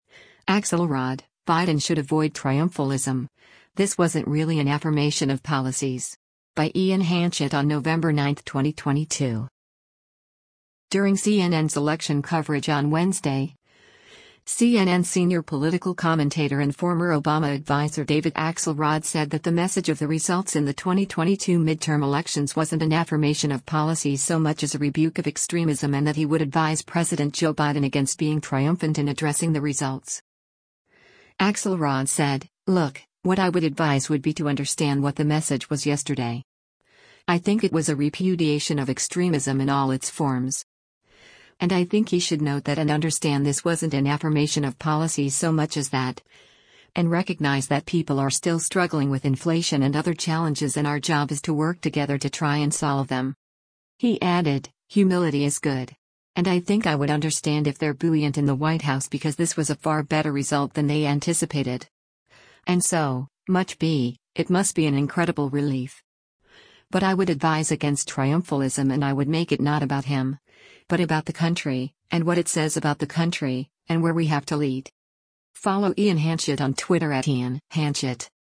During CNN’s election coverage on Wednesday, CNN Senior Political Commentator and former Obama adviser David Axelrod said that the message of the results in the 2022 midterm elections “wasn’t an affirmation of policies so much” as a rebuke of extremism and that he would advise President Joe Biden against being triumphant in addressing the results.